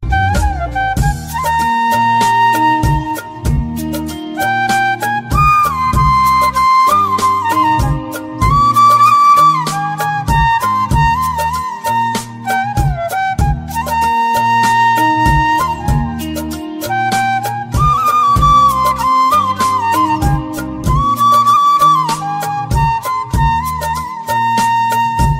Category: Flute Ringtones